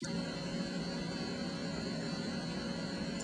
chut.wav